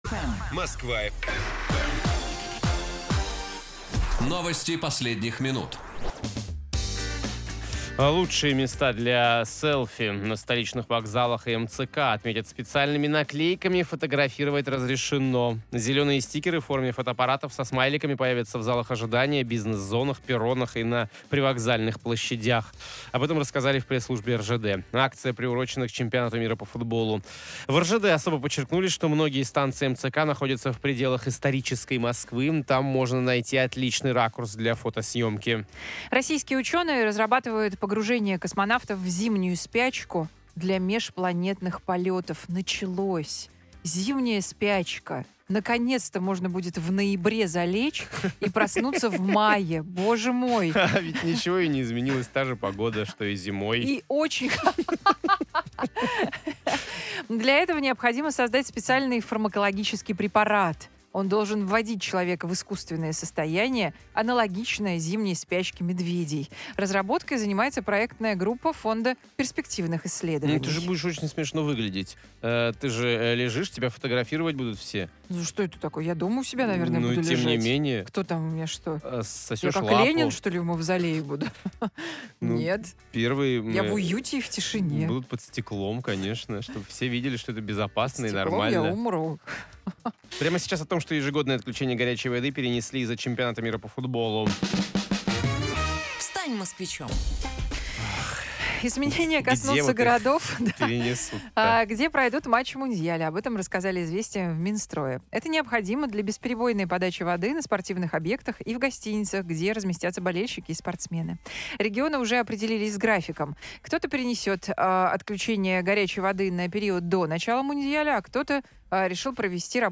в прямом эфире на радиостанции Москва-FM